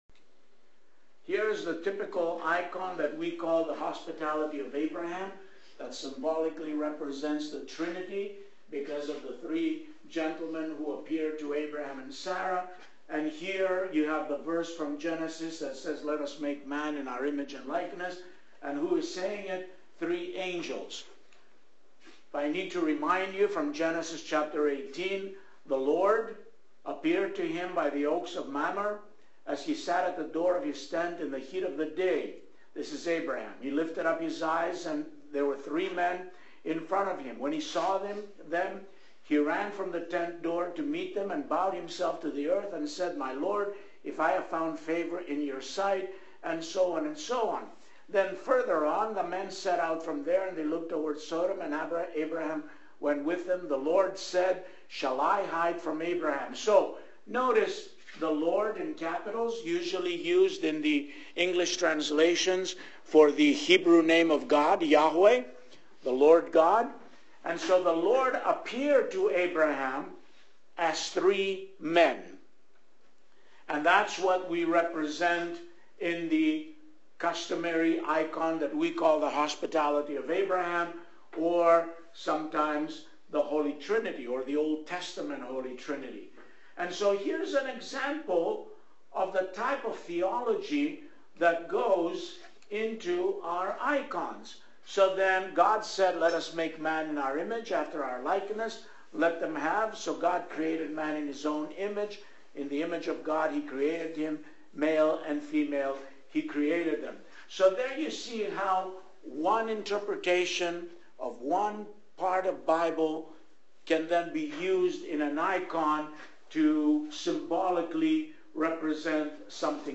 The first in a short series of Bible Study classes used the liturgical texts of the First Sunday of Lent (Sunday of Orthodoxy) to illustrate several theological precepts of the Orthodox Church as well as aspects of typology in biblical exegesis and iconography. An audio file of the class is attached, together with a PDF version of the PowerPoint presentation. Several minutes at the beginning of the class and the discussion period at the end were cut to make the file more amenable to online listening.